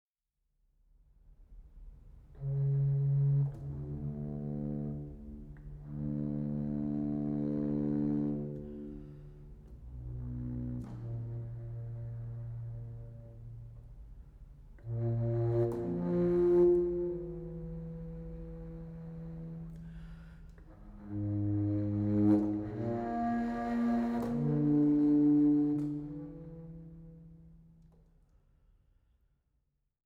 Neue Musik für Flöte und Orgel (II)
Flöte
Orgel